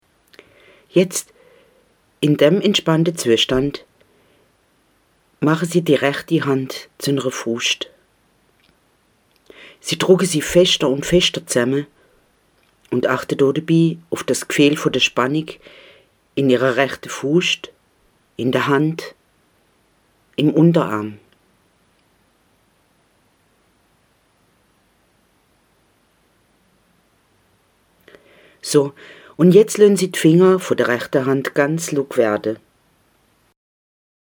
Ich finde die richtigen Worte für Sie– au uff alemannisch. Hier ein Hörbeispiel: